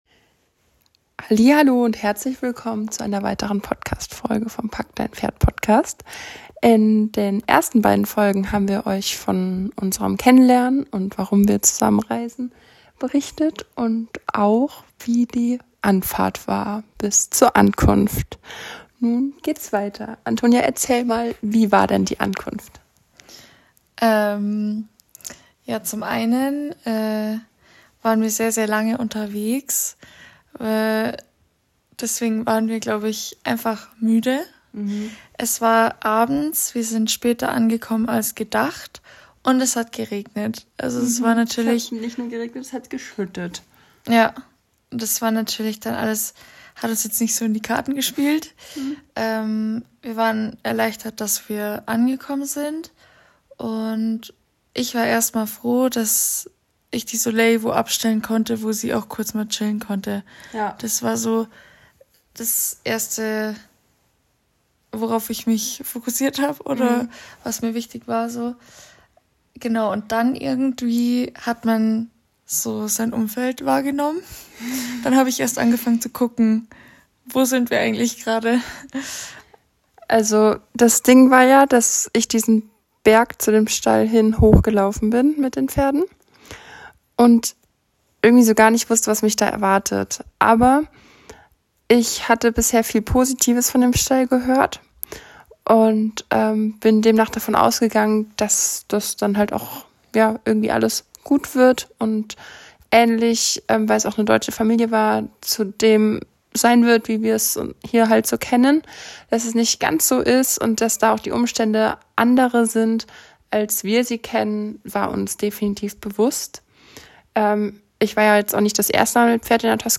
Wir freuen uns über Euer Feedback :) PS: die Aufnahme dieses Podcasts erfolgte sehr spontan im Bett. Daher verzeihe bitte die Tonqualität, wir wollten Dir unsere Erlebnisse dennoch nicht vorenthalten.